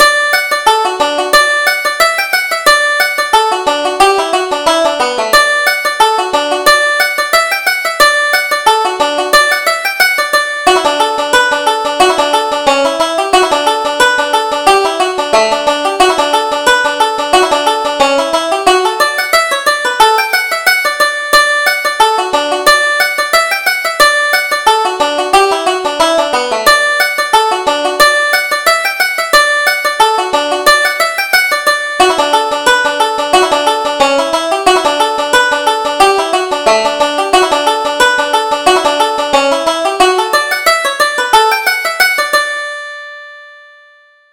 Reel: Bill Clancy's Delight